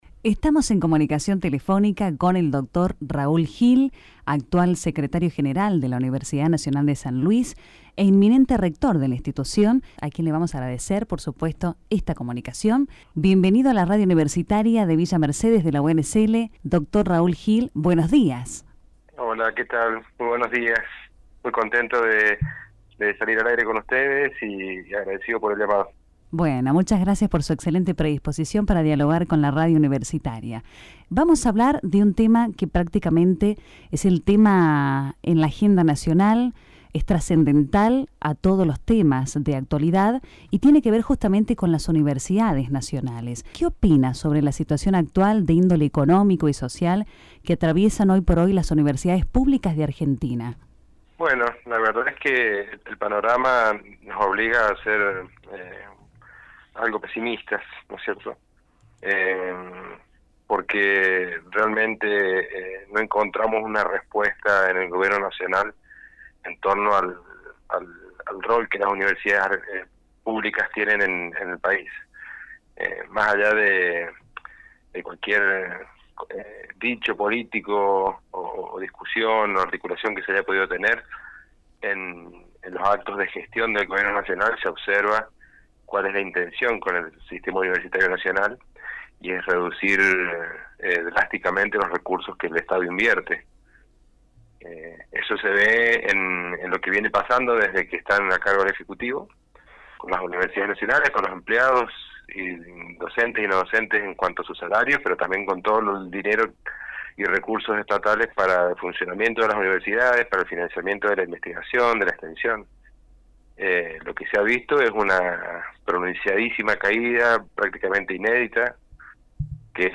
Dialogamos con el Dr. Raúl Gil, nuevo rector de la UNSL, sobre la situación actual que atraviesan las Universidades Públicas de Argentina, luego de que el Ejecutivo nacional vetara la Ley de Financiamiento Universitario, y en el marco de una convocatoria nacional a la “Marcha Federal” prevista para el día de hoy.